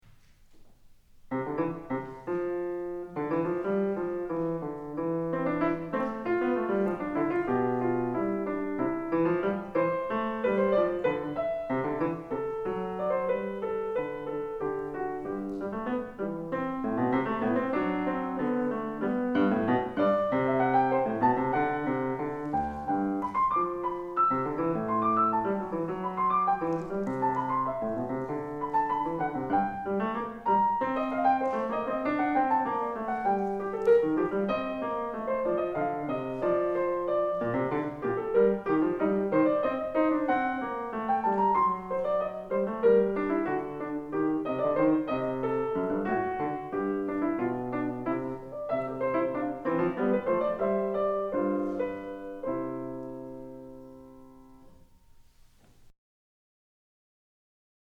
自作自演
2002.3.3 イズミティ２１ 小ホール
自分の作品なのにミスタッチ多いです・・・。